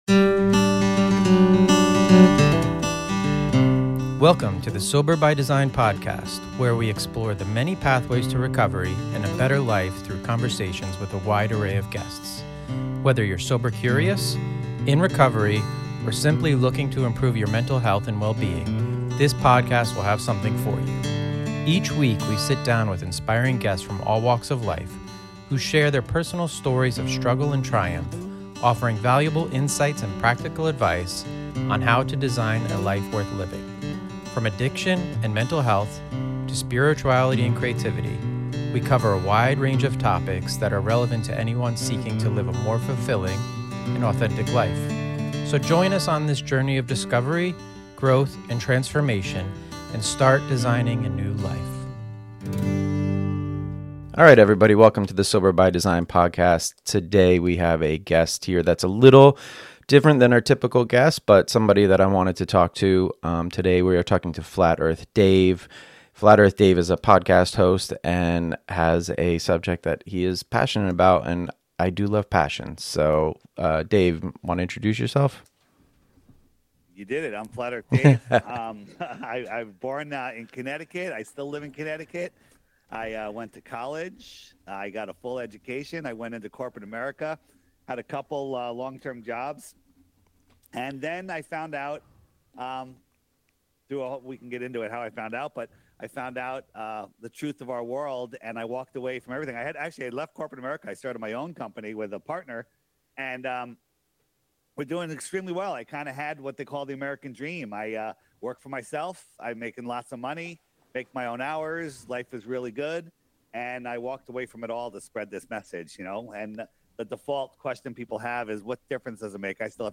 Using language I learned on the podcast, we have a very kind and open discussion between a glober and a flat earther.